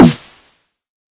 冲锋号
标签： 冲锋号 小号 音效